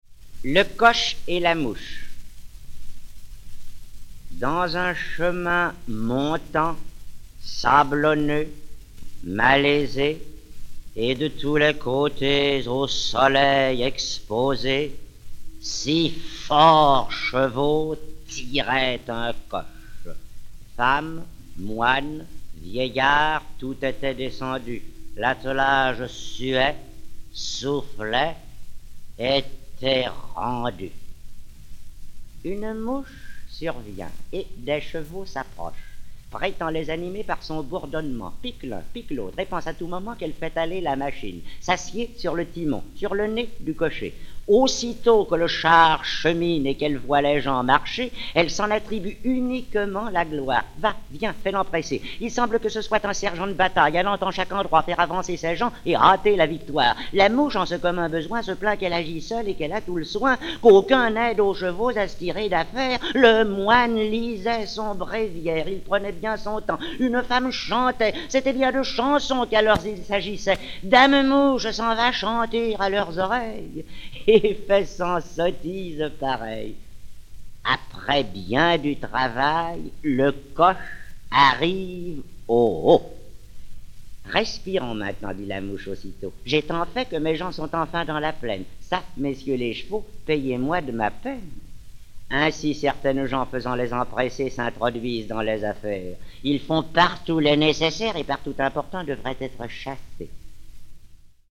fable (Jean de La Fontaine)
Georges Berr, sociétaire de la Comédie-Française